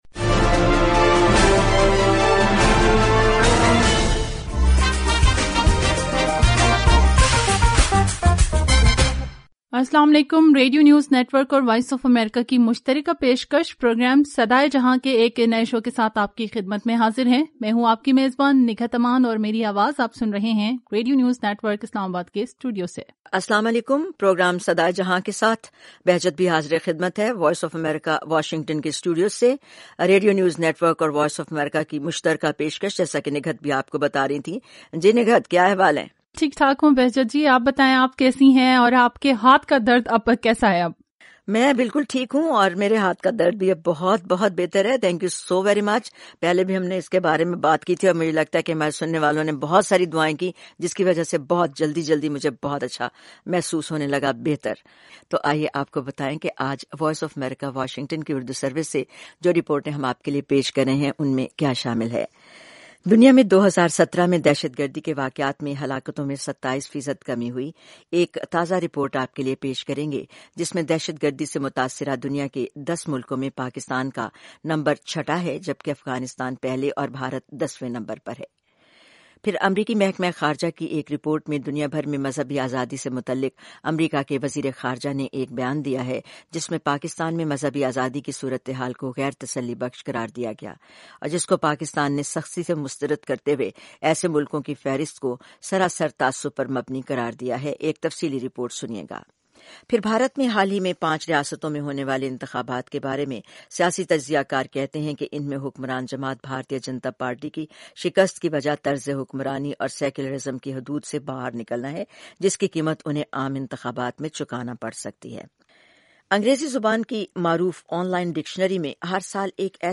گرلز آن وہیلز ۔ ۲۰۱۸ کا منتخب لفظ اور شو بز راونڈ اپ کے ساتھ ساتھ شمع سکندر کا انٹرویو